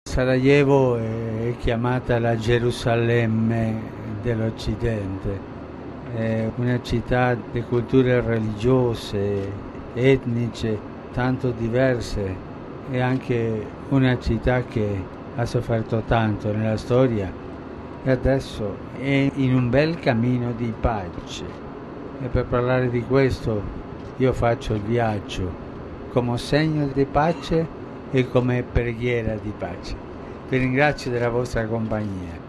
Durante il volo, Francesco ha rivolto qualche parola ai rappresentanti dei media.